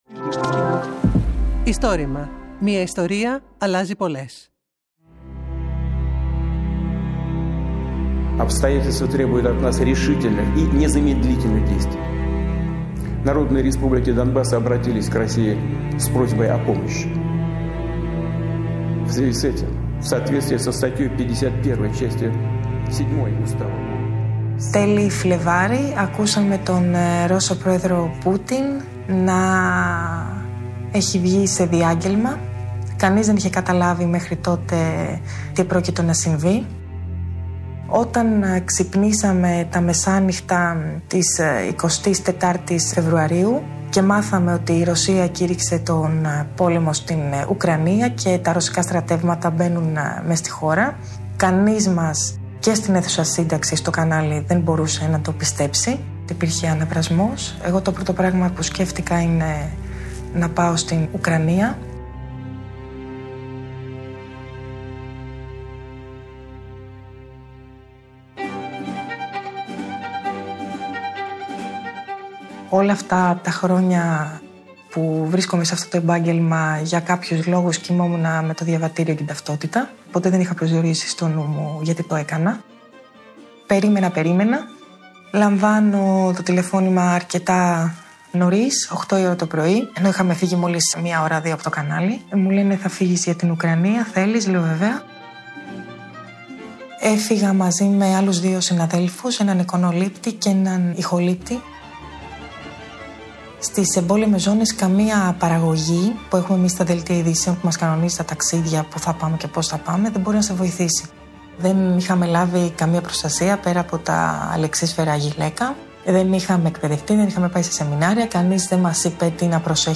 Αφηγήτρια
Το Istorima είναι το μεγαλύτερο έργο καταγραφής και διάσωσης προφορικών ιστοριών της Ελλάδας.